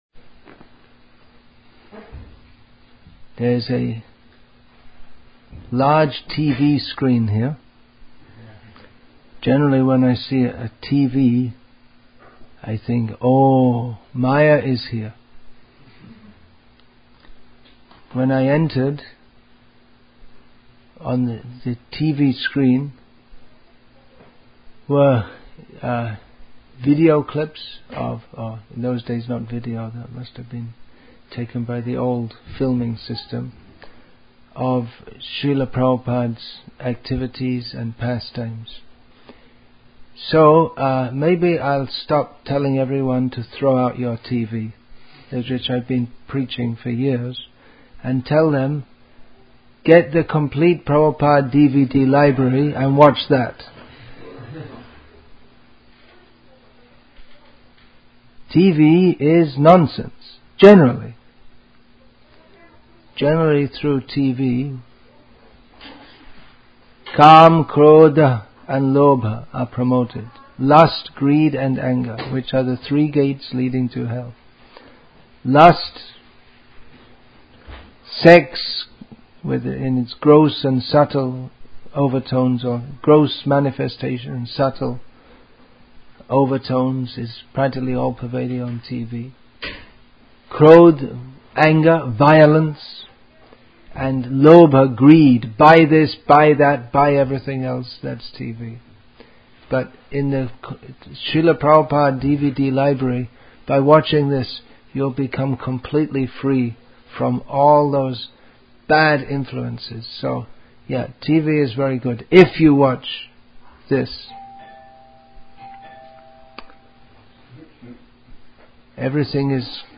Assorted Lectures